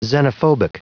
Prononciation du mot xenophobic en anglais (fichier audio)
Prononciation du mot : xenophobic